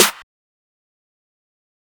GW Snare.wav